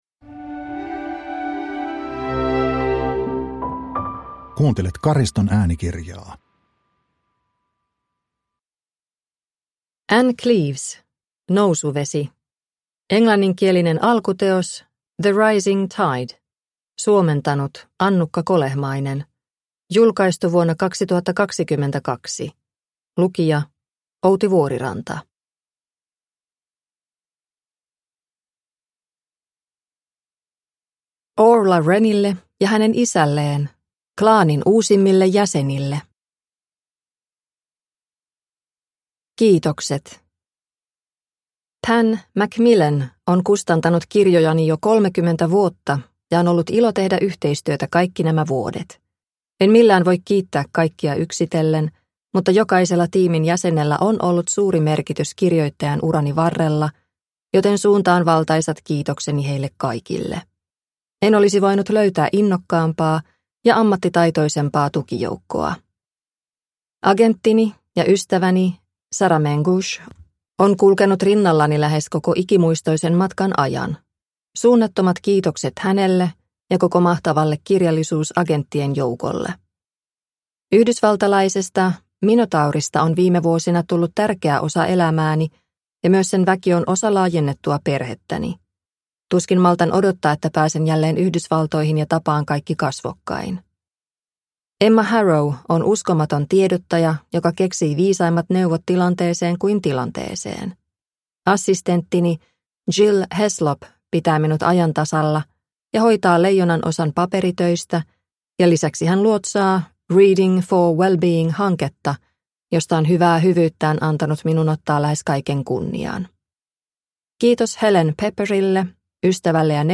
Nousuvesi – Ljudbok – Laddas ner